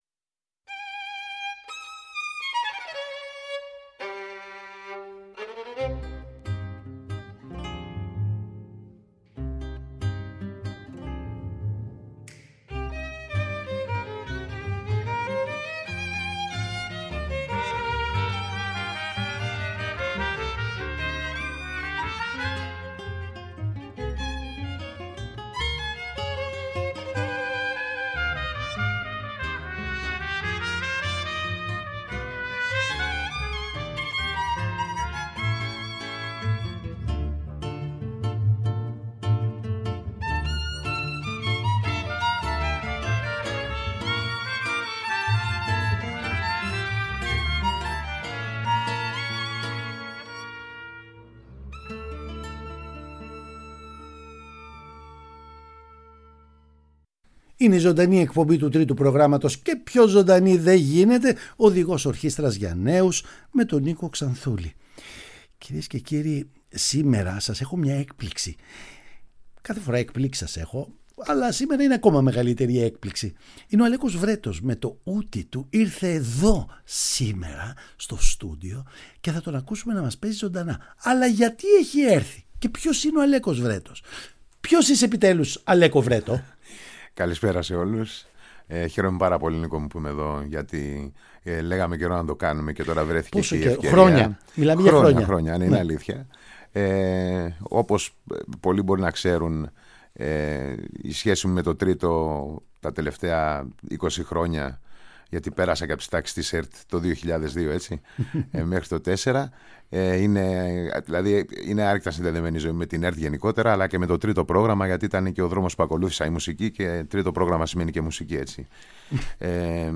Μουσικη Jazz
Ουτι